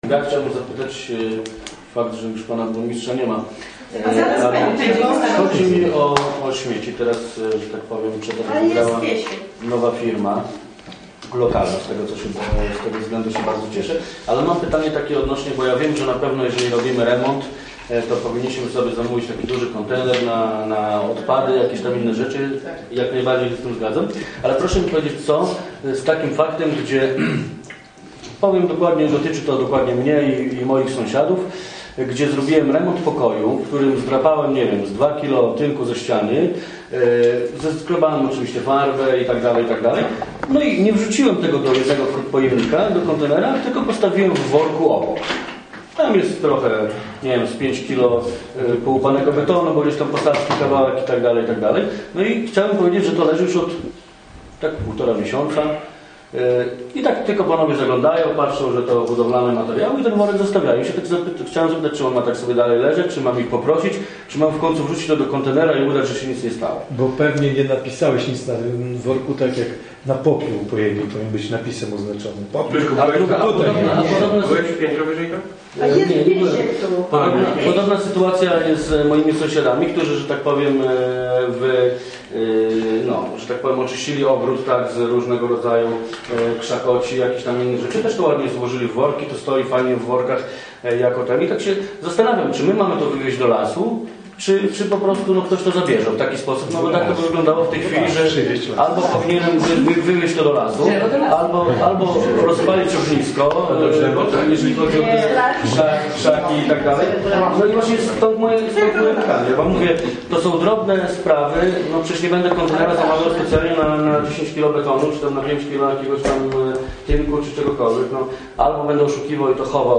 mówi Zbigniew Makarewicz